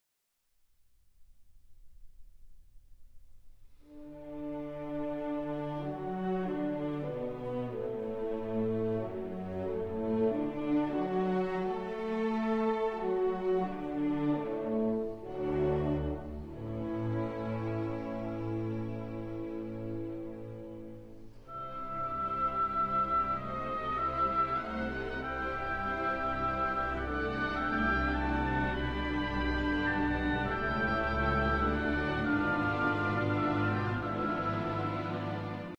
Allegro non troppo